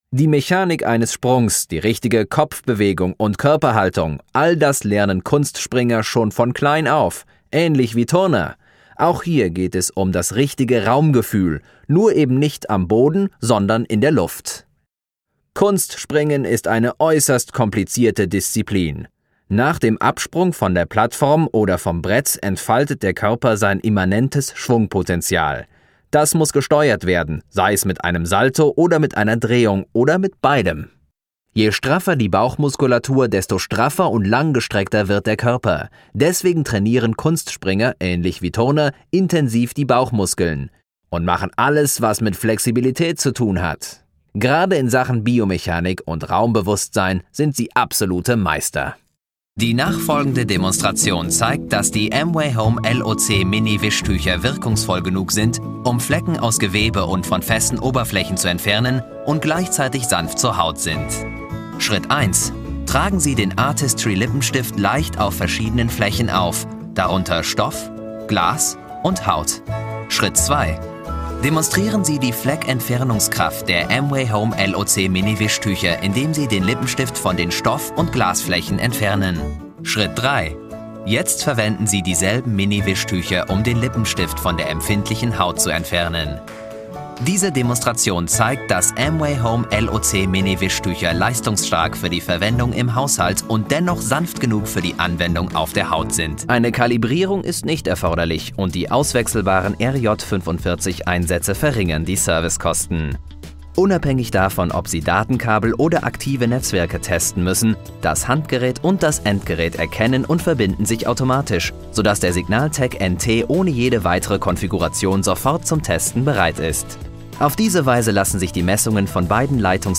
German. Actor, young, flexible, experienced.
German Commercials